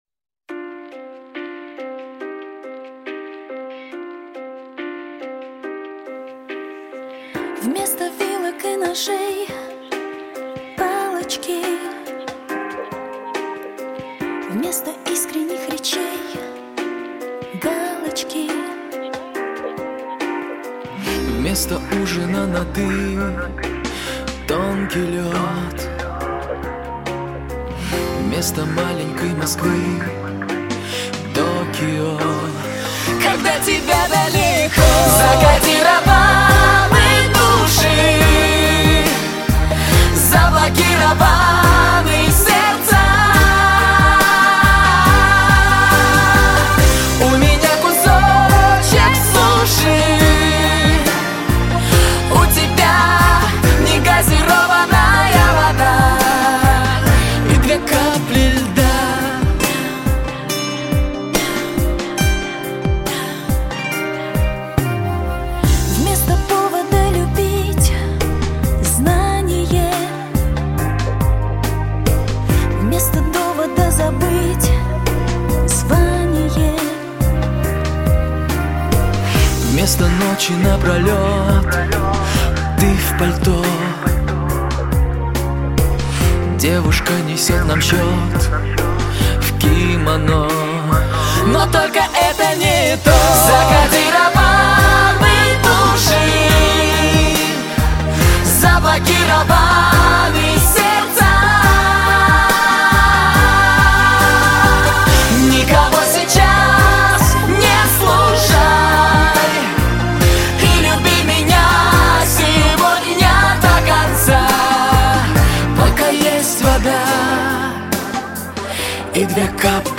дуэт